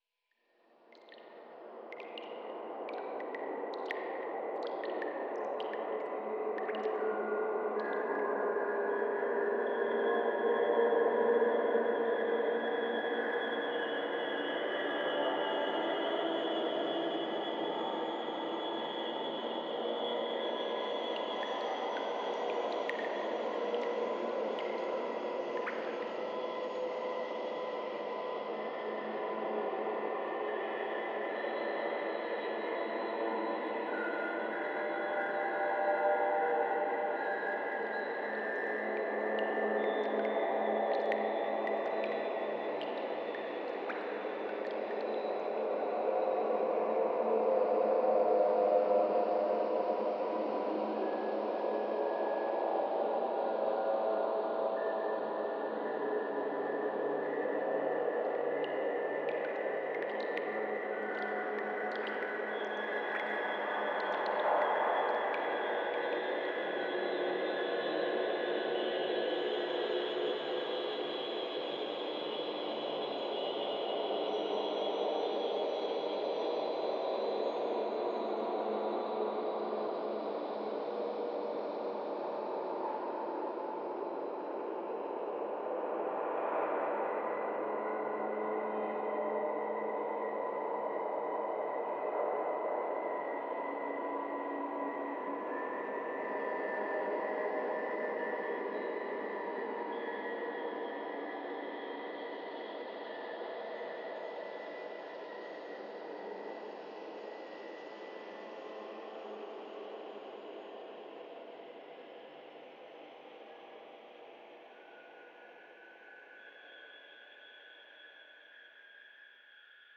09_裂缝空间_地下通道.wav